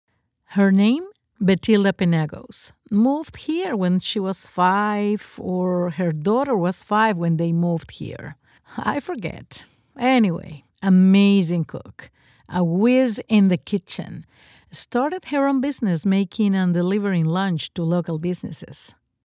English Latin American accent voice over